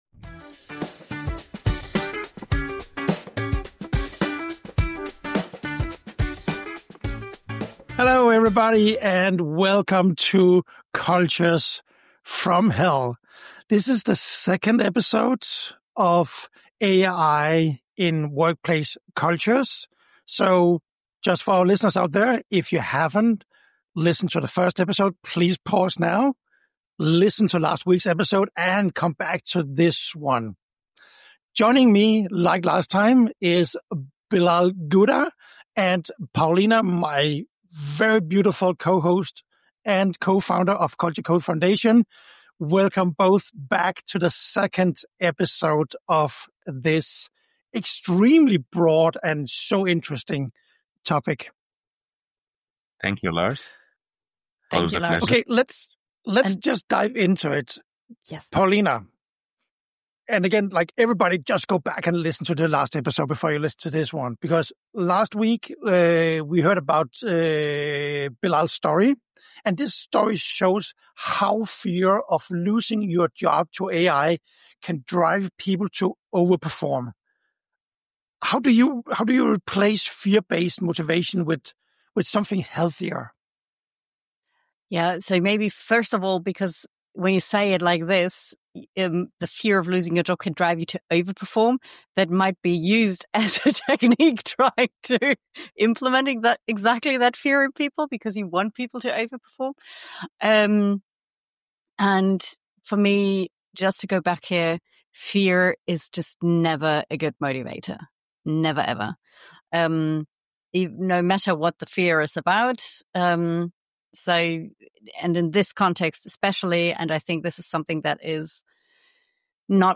They explore the shift from fear-based motivation to empowerment, the role of AI in enhancing employee well-being, and the necessary leadership mindset for integrating AI effectively. The conversation emphasizes the importance of measuring productivity by outcomes rather than outputs, rethinking productivity metrics in the AI era, and maintaining cultural values amidst a focus on speed and efficiency.